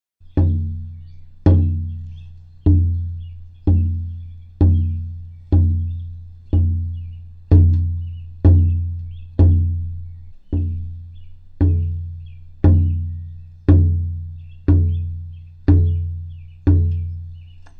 印第安打击乐循环 " 印第安打击乐循环1
Tag: 印度 打击乐